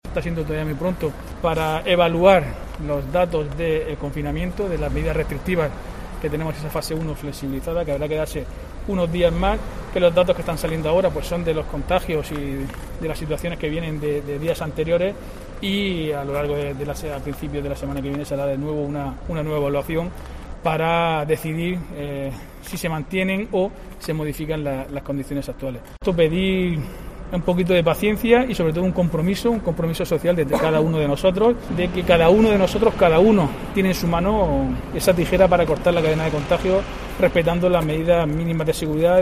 Diego José Mateos, alcalde de Lorca sobre análisis fase 1